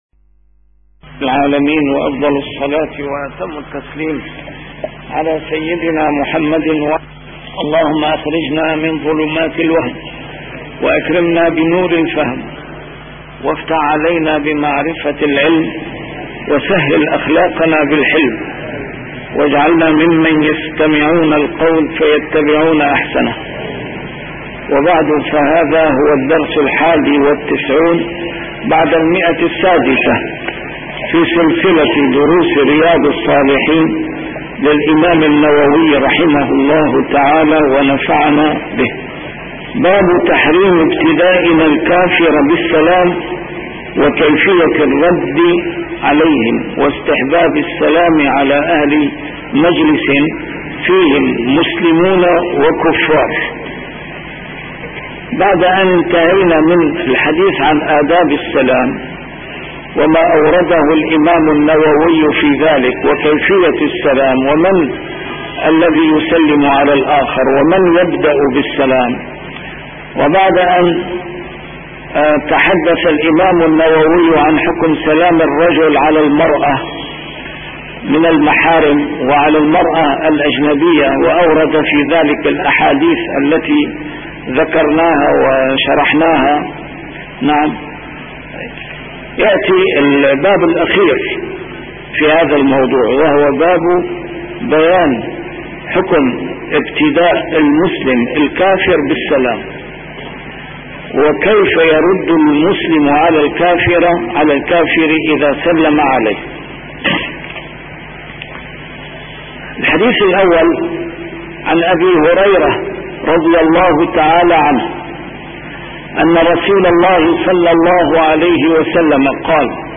A MARTYR SCHOLAR: IMAM MUHAMMAD SAEED RAMADAN AL-BOUTI - الدروس العلمية - شرح كتاب رياض الصالحين - 691- شرح رياض الصالحين: تحريم ابتداء الكافر بالسلام